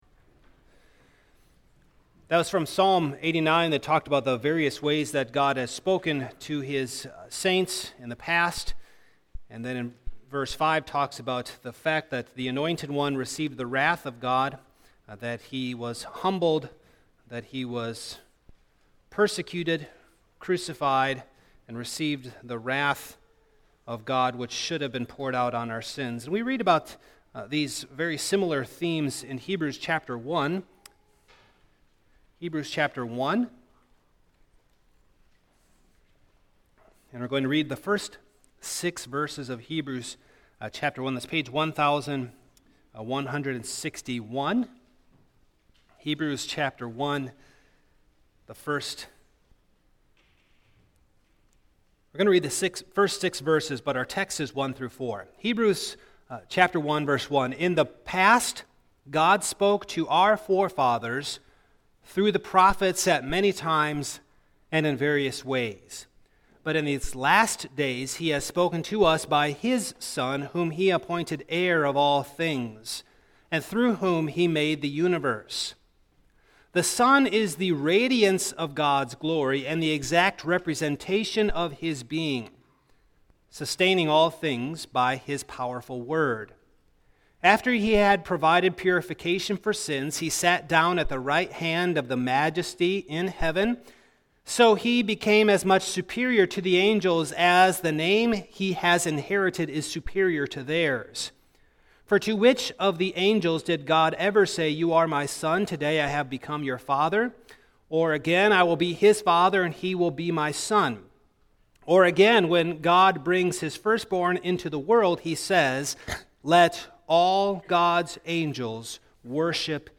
Series: Single Sermons
Service Type: Morning